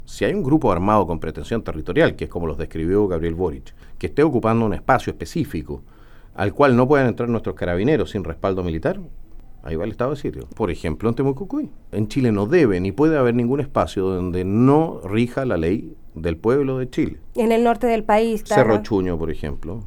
Acompañado de adherentes y en medio de la plaza Aníbal Pinto de Temuco, región de La Araucanía, el candidato presidencial del Partido Nacional Libertario, Johannes Kaiser, respondió las preguntas de la ciudadanía que plantearon sus inquietudes.